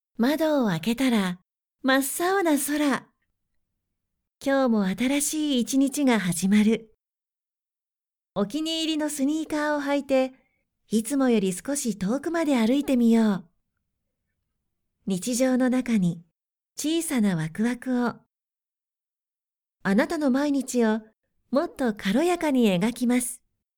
クセのない素直な声質で、明るく元気なものから落ち着いたものまで対応可能です。
– ナレーション –
ストレート
female59_10.mp3